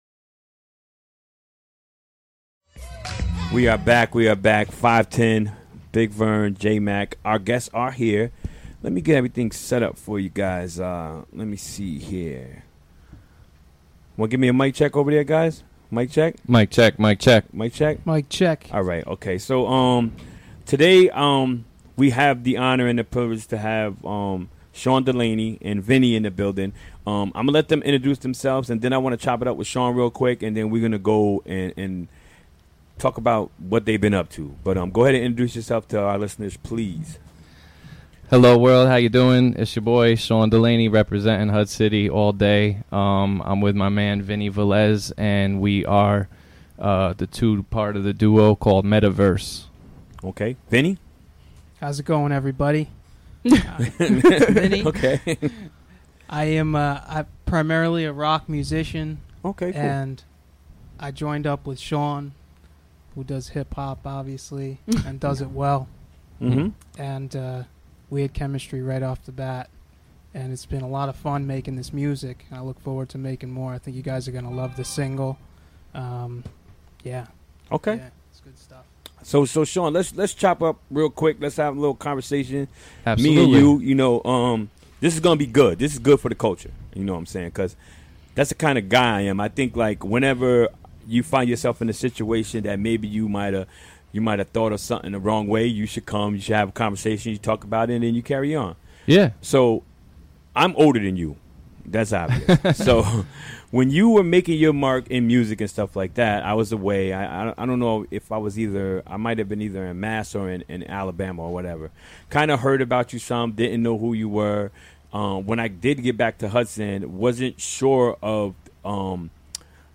Interviewed
Recorded during the WGXC Afternoon Show Wednesday, August 2, 2017.